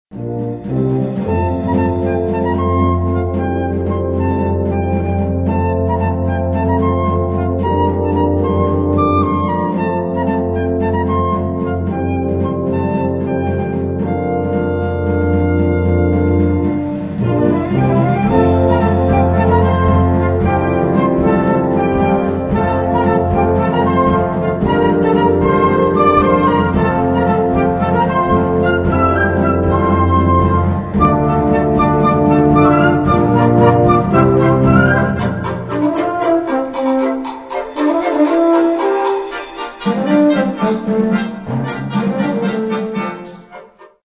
Catégorie Harmonie/Fanfare/Brass-band